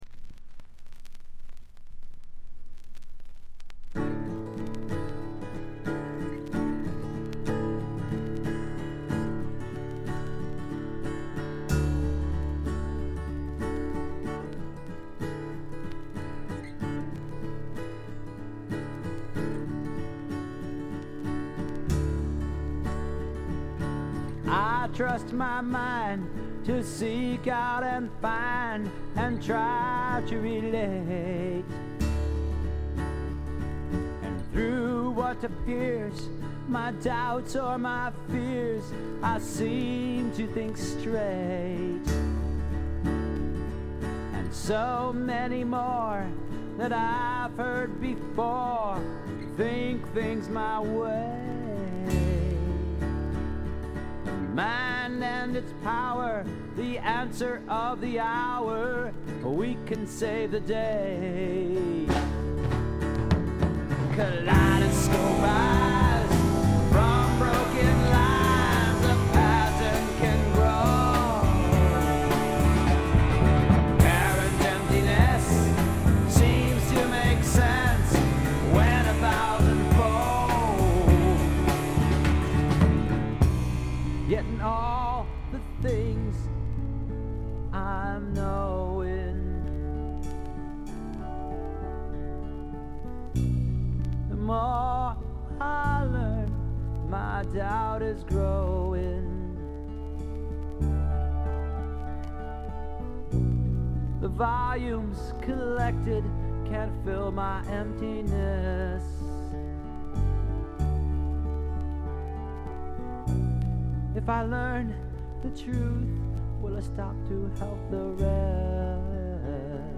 ホーム > レコード：米国 SSW / フォーク
自主制作オリジナル盤。
試聴曲は現品からの取り込み音源です。